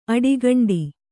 ♪ aḍigaṇḍi